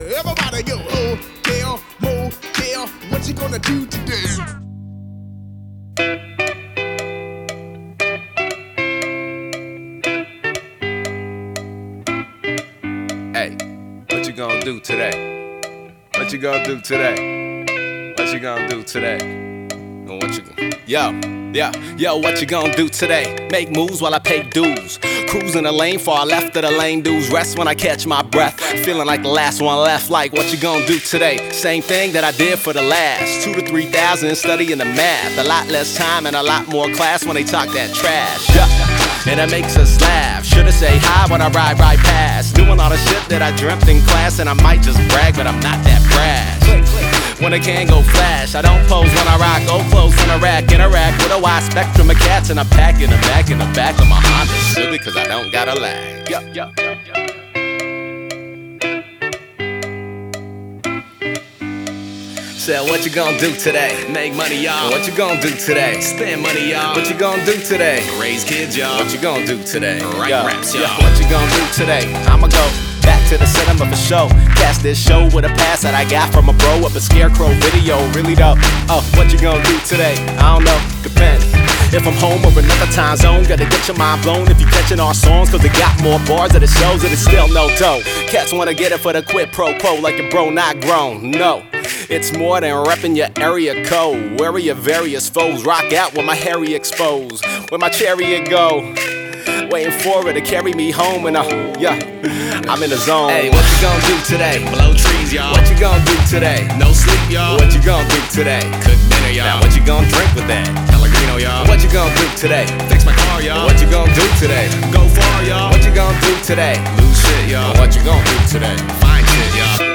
It’s catchy. It’s freewheeling.